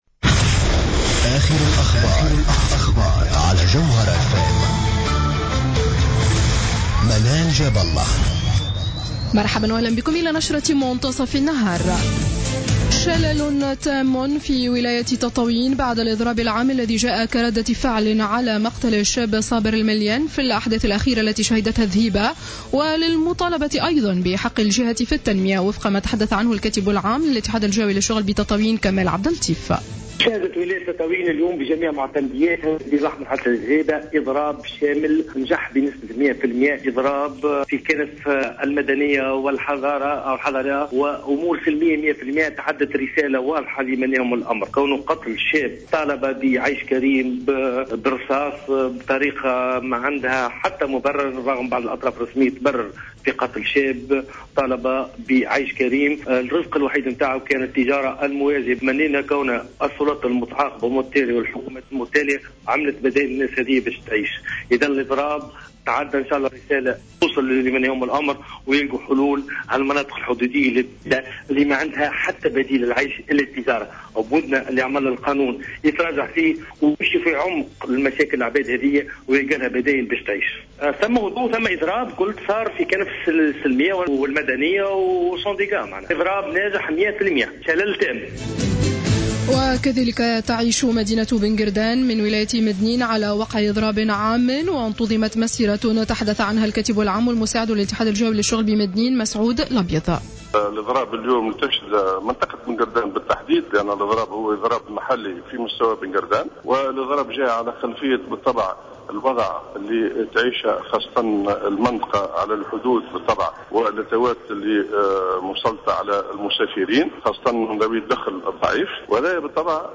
نشرة أخبار منتصف النهار ليوم الثلاثاء 10 فيفري 2015